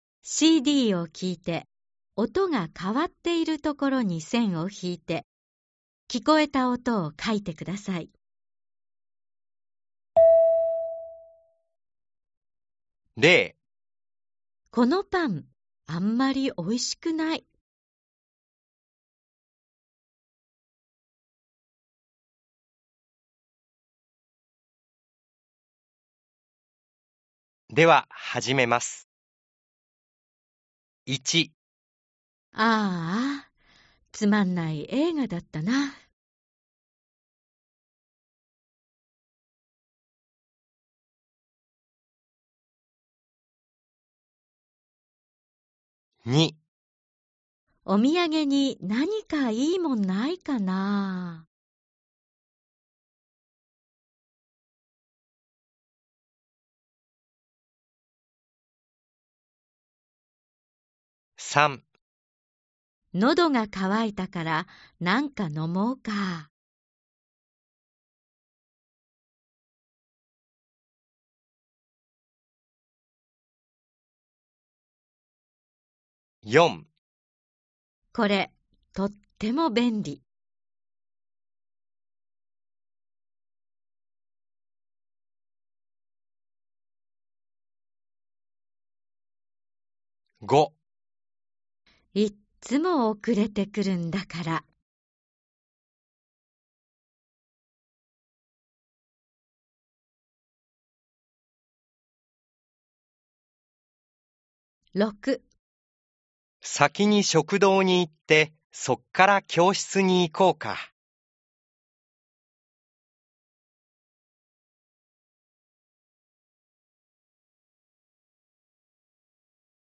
Đây là cách nói khá thân mật.